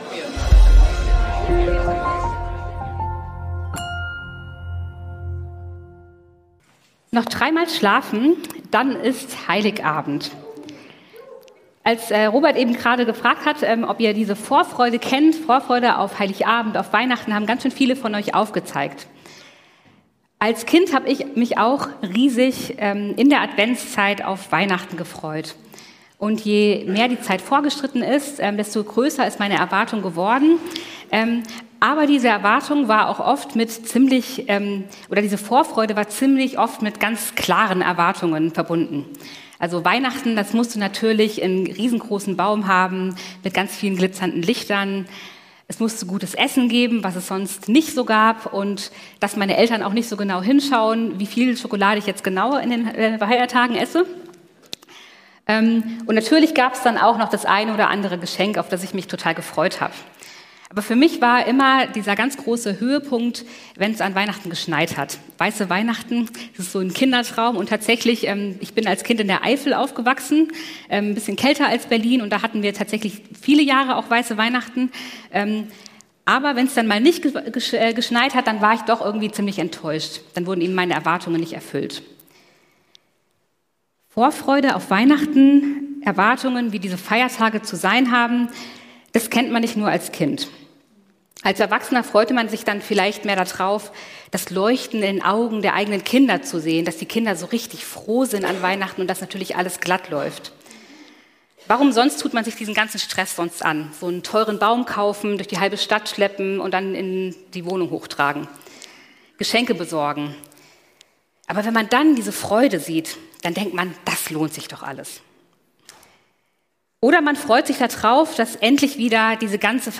Hoffnung - zwischen Erwartung und Wirklichkeit ~ Predigten der LUKAS GEMEINDE Podcast